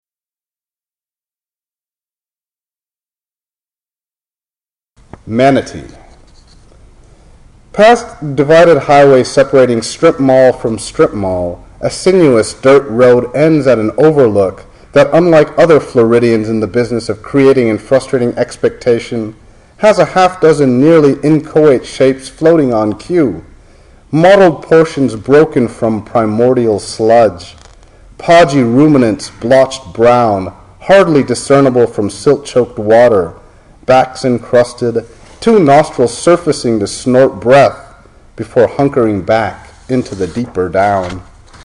Bowdoin College, Brunswick, Maine, June 17, 2005.
Manatee_live.mp3